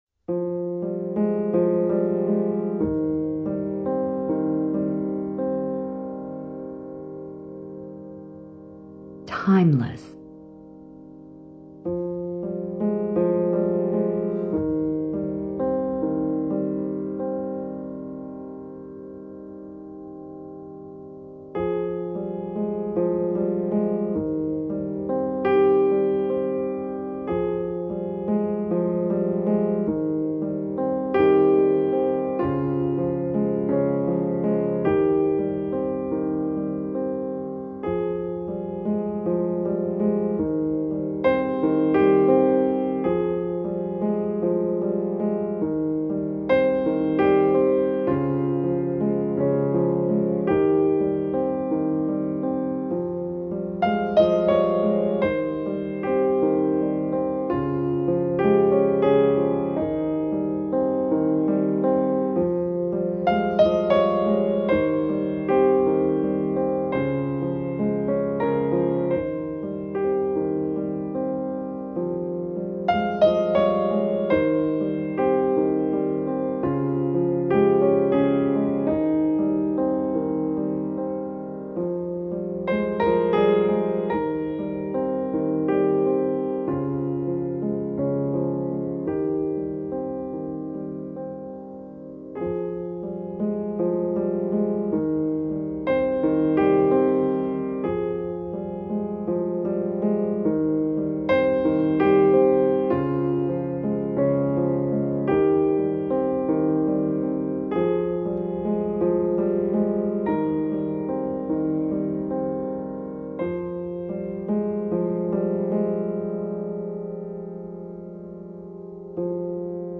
Эта изысканная  композиция фортепиано